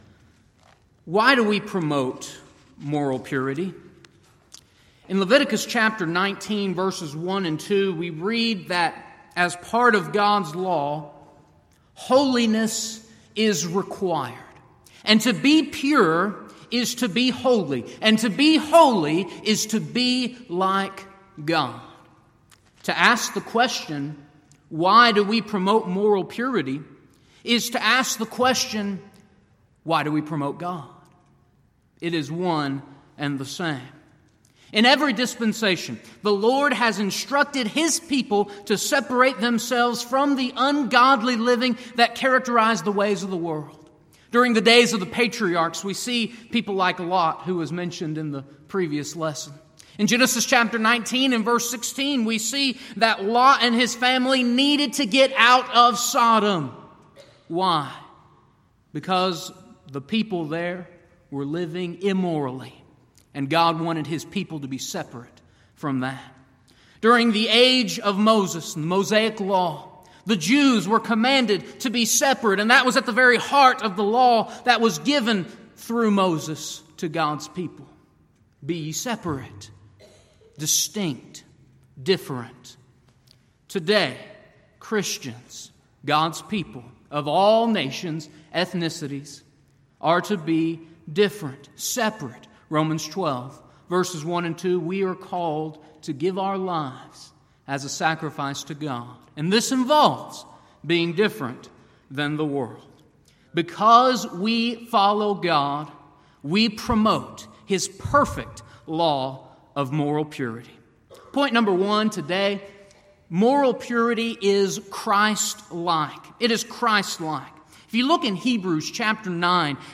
Alternate File Link File Details: Series: Southwest Lectures Event: 32nd Annual Southwest Lectures Theme/Title: Why Do We...